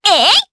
Aisha-Vox_Attack4_jp.wav